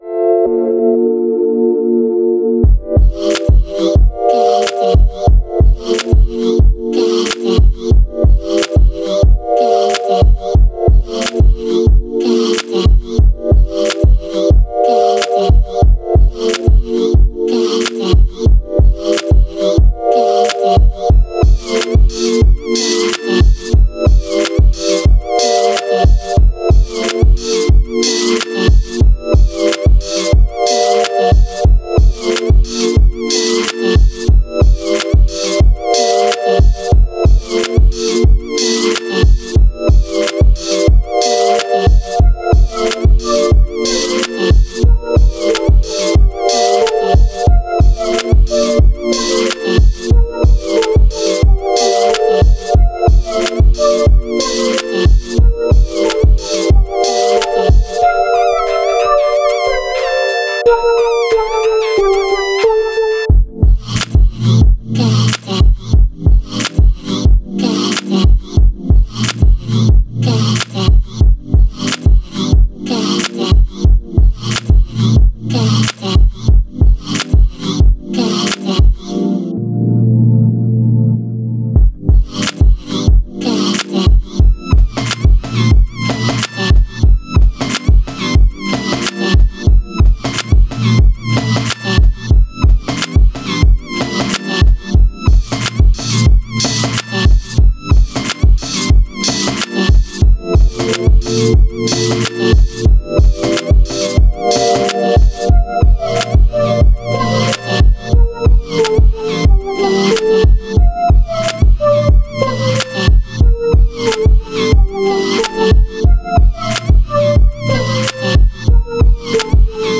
Underground Hip Hop
beat tape…Very chill and exotic